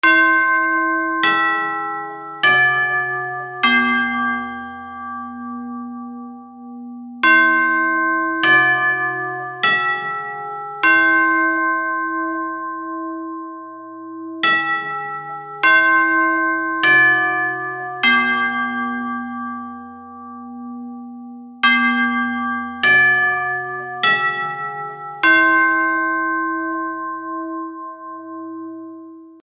/ G｜音を出すもの / G-01 機器_チャイム
チャイム 03-02 鐘 学校のチャイム 始業 終業 ウェストミンスターの鐘
『キーンコーンカーンコーン』 Low Pitch